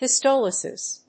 発音
• : -ɒlɪsɪs